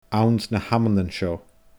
anns na h-amannan seo /ãũNs nə haməNən ʃɔ/